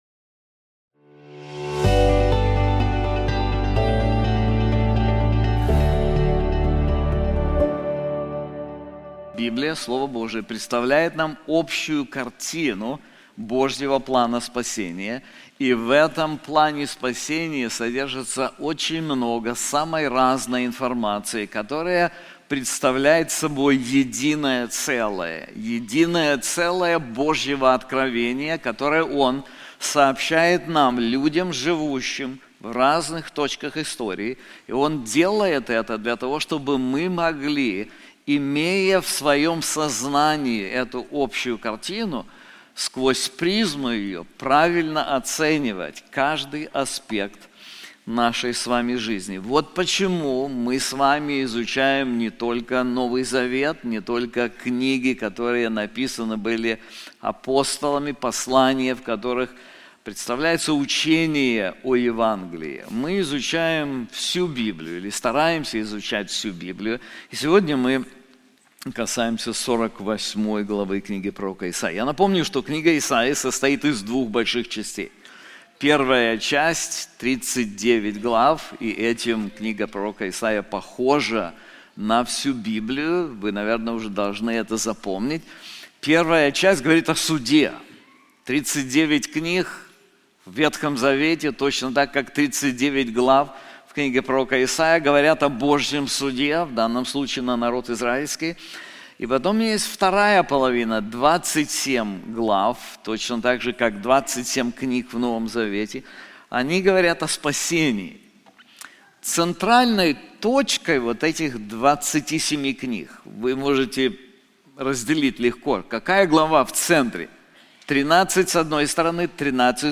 This sermon is also available in English:The Power of God's Glory • Isaiah 48:1-12